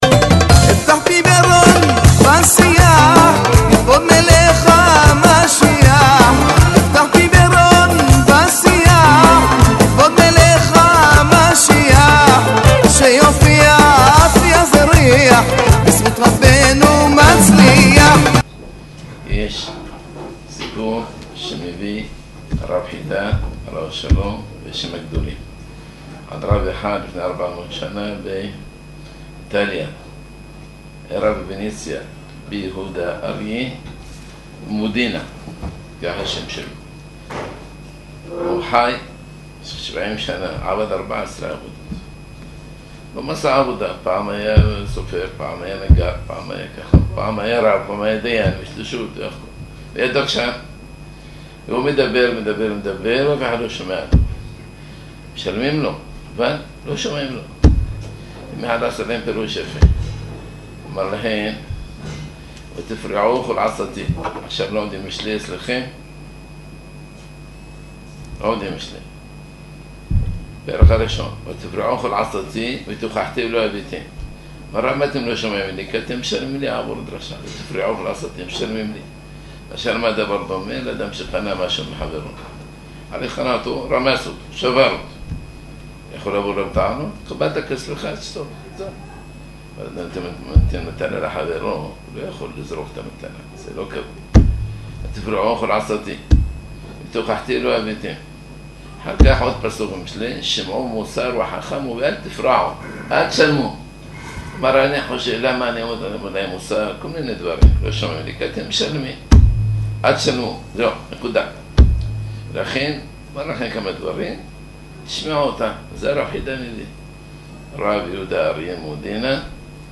דברי מו''ר הגאון ראש הישיבה הרב מאיר מאזוז שליט''א מתוך כינוס שנתי הנערך לכלל מלמדי התלמודי תורה שתחת כנפי ישיבתנו הקדושה